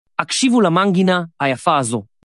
智东西8月7日报道，今天，MiniMax推出新一代语音生成模型Speech 2.5